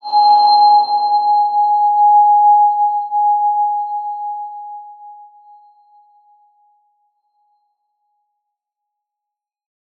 X_BasicBells-G#3-mf.wav